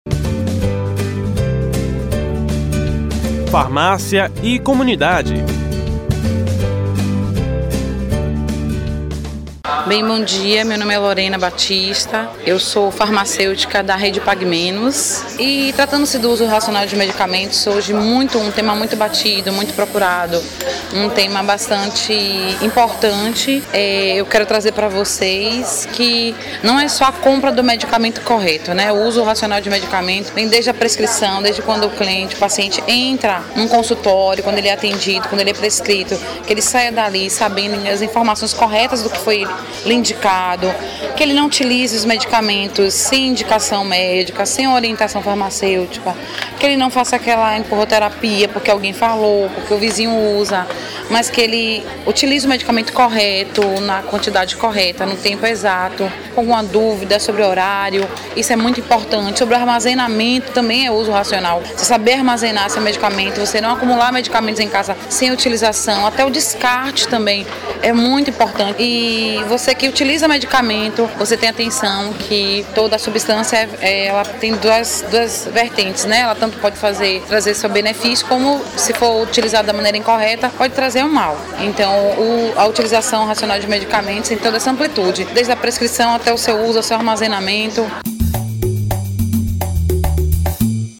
O assunto foi tema do Quadro Farmácia e Comunidade, que vai ao ar toda terça-feira, no Programa Saúde no ar, veiculado pela  Rede Excelsior de Comunicação: AM 840, FM 106.01, Recôncavo AM1460 e Rádio Saúde no ar.